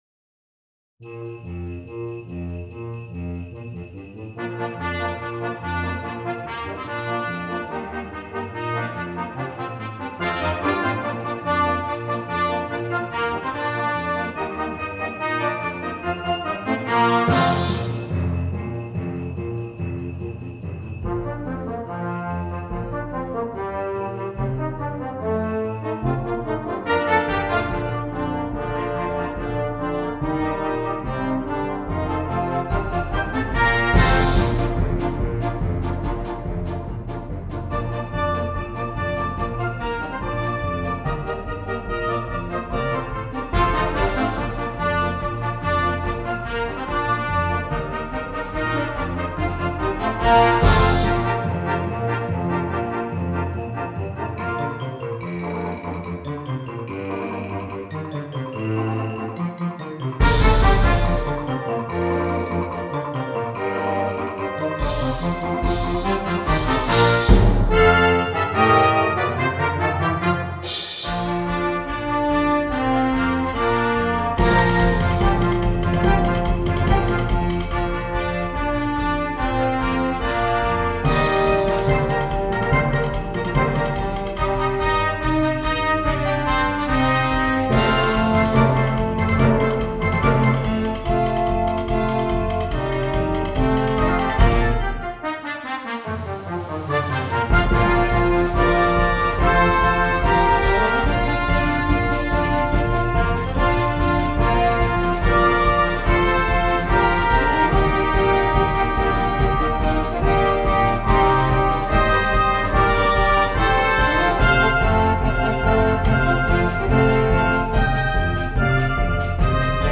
Concert Band
Traditional Carol
high energy piece